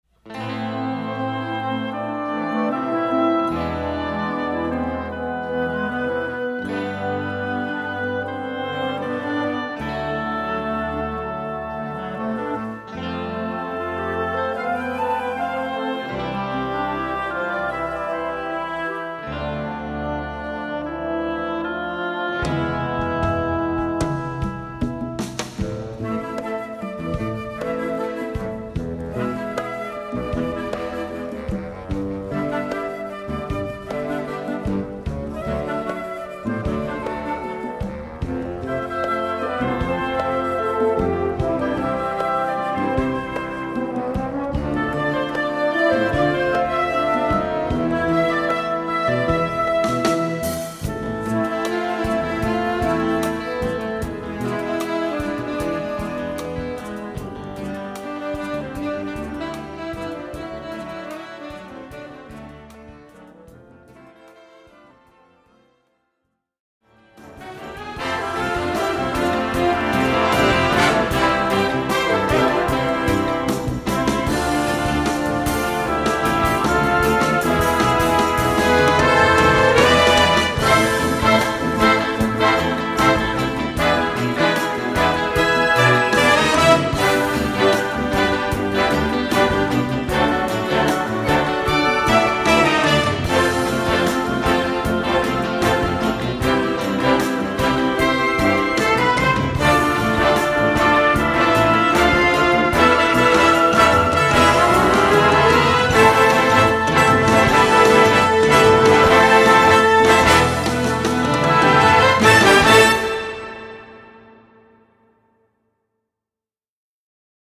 Besetzung: Blasorchester
(inklusive optionale E-Gitarrenstimme)